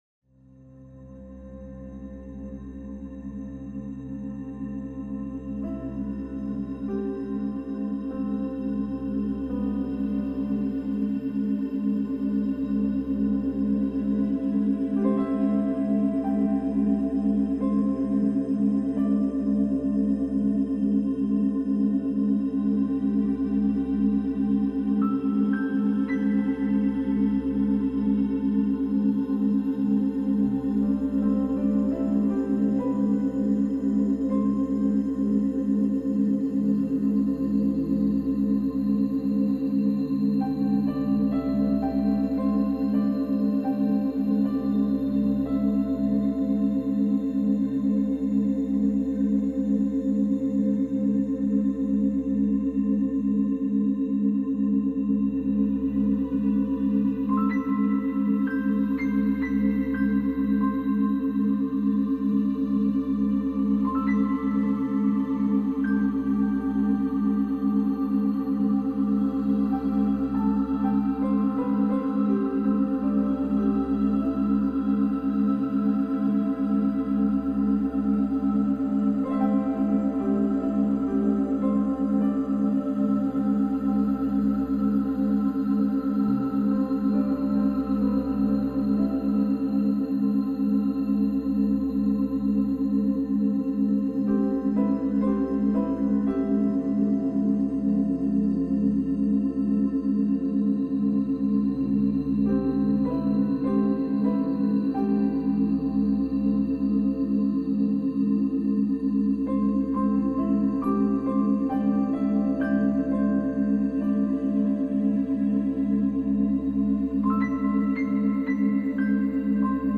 Musique relaxation, un apaisant total
2025 MUSIQUE RELAXANTE, EFFETS SONORES DE LA NATURE audio closed https
musique-relaxation-pour-un-relachement-total.mp3